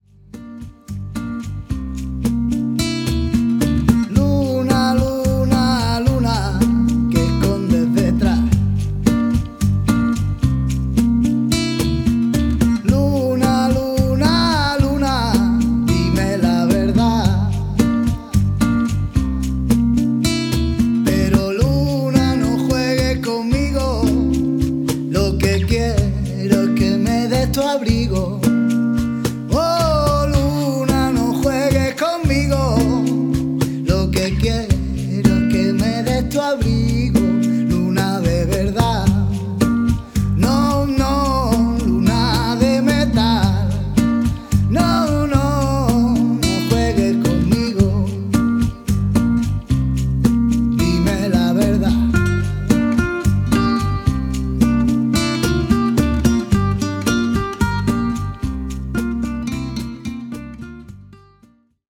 WORLD MUSIC